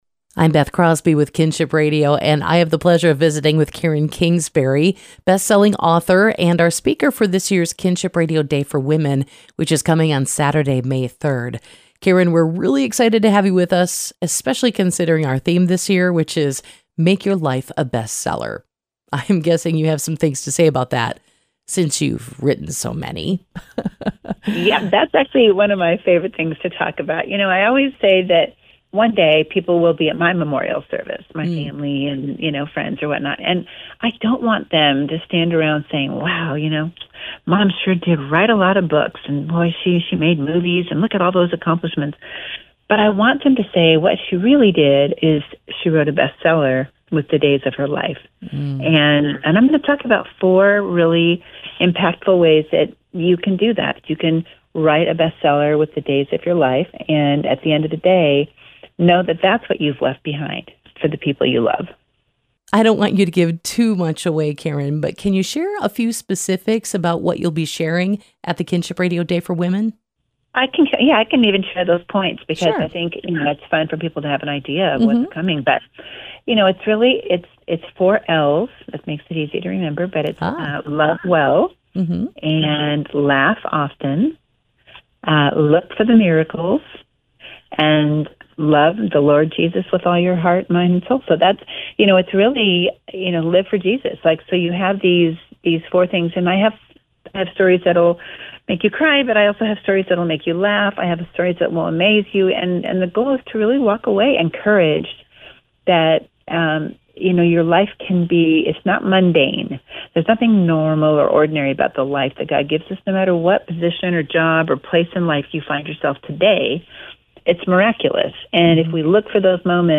INTERVIEW-Karen-Kingsbury-part-two-final.mp3